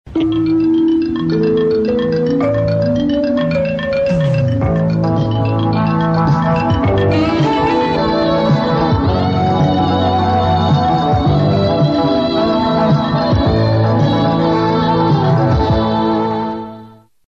Sintonia de la cadena